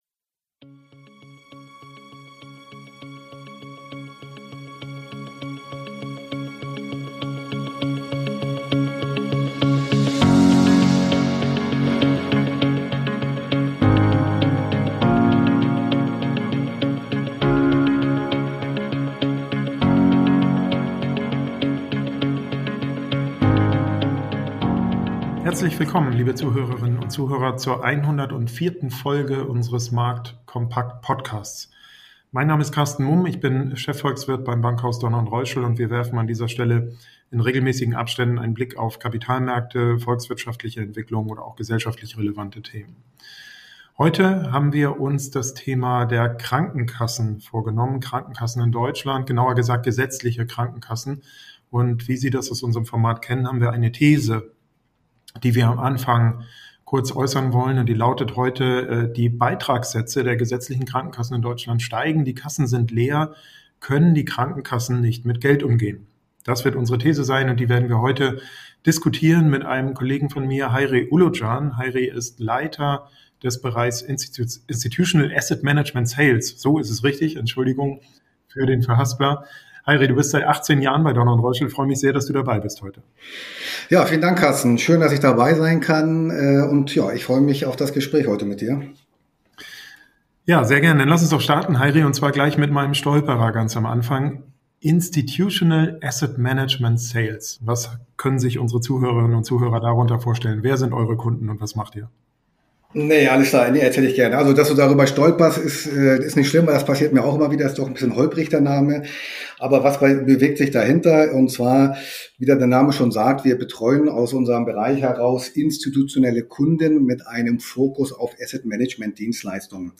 Ein Gespräch über Verantwortung, Reformbedarf und die Frage: Was muss passieren, damit die Krankenkassen wieder gesunden?